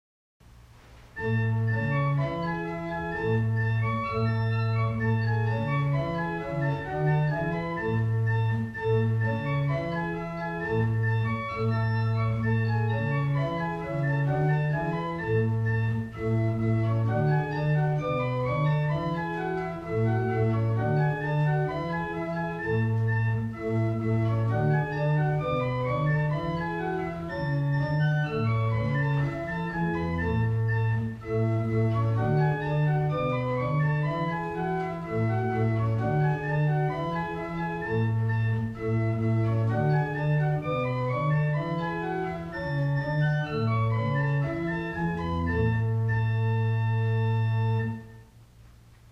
1802 Tannenberg Organ
Hebron Lutheran Church - Madison, VA
Listen to Country Dance: The Lucky Hit by Alexander Reinagle played on Gedackt 8' and Principal 4' by clicking